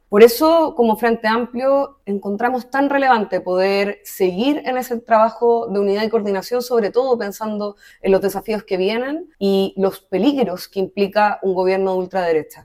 Bajo ese contexto, la timonel del FA, Constanza Martínez, recalcó que la responsabilidad de construir la alianza recae en los partidos y sus directivas.